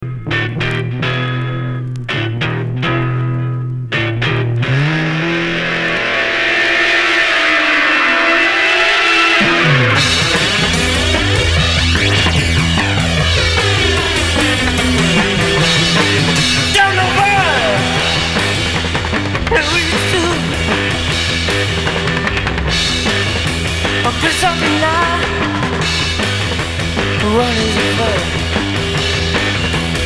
Heavy rock psychédélique Deuxième 45t retour à l'accueil